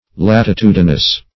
Latitudinous \Lat`i*tu"di*nous\, a.
latitudinous.mp3